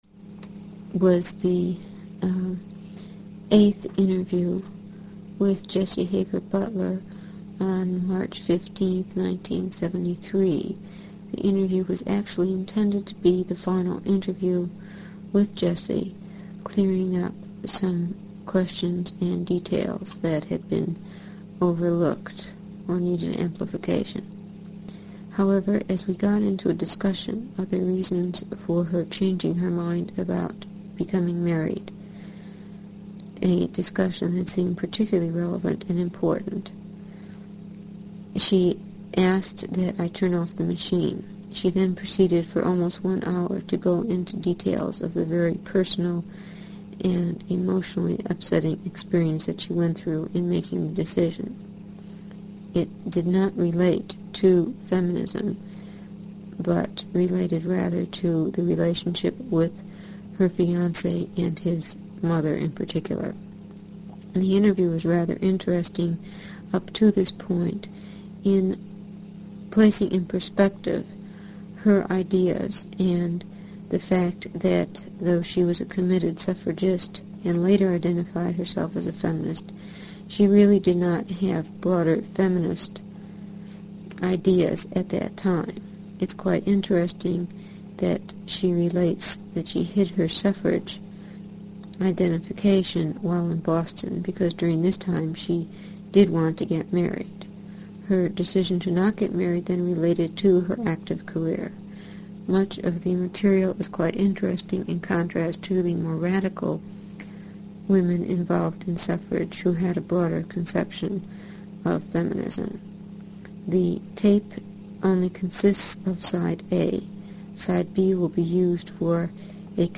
INTERVIEW DESCRIPTION - This interview was intended as the final interview to clear up some questions and details that were overlooked and required elaboration.